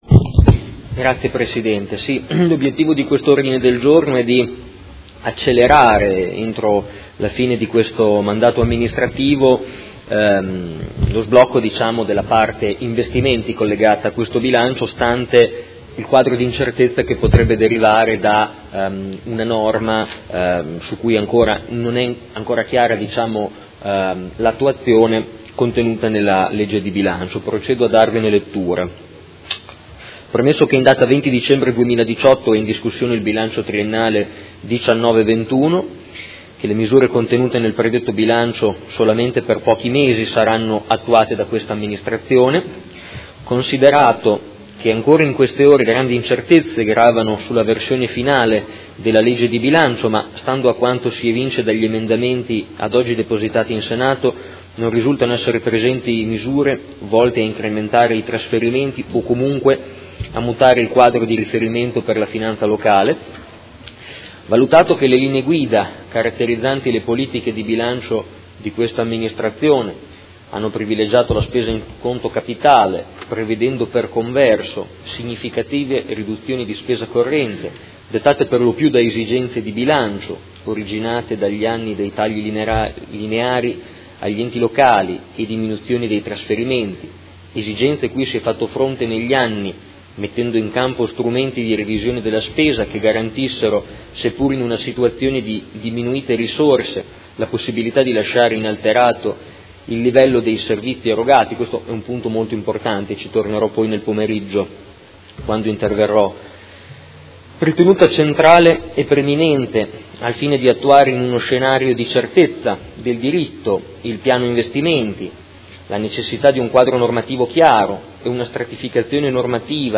Seduta del 20/12/2018. Presenta Ordine del Giorno Prot. Gen. 207682